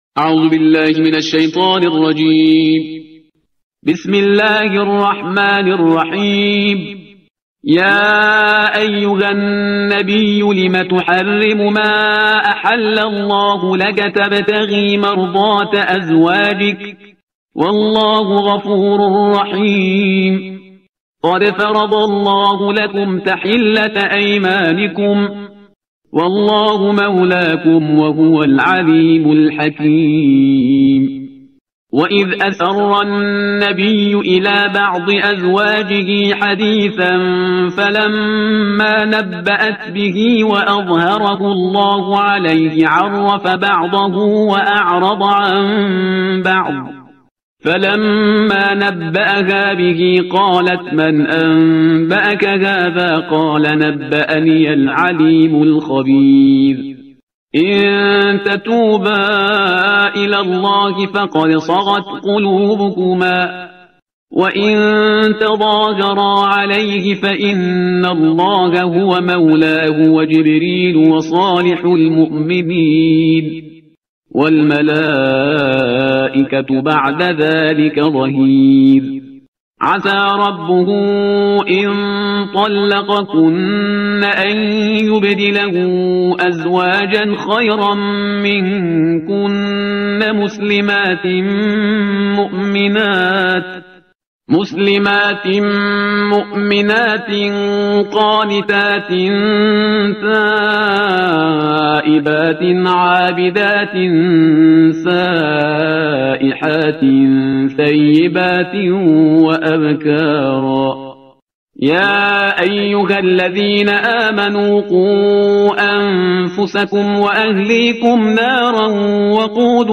ترتیل صفحه 560 قرآن